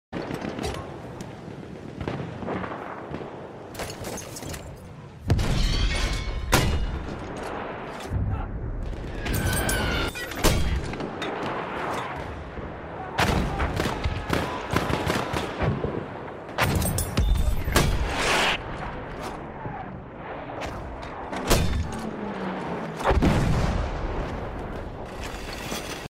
Battlefield 1 Long Range Motar Double Sound Effects Free Download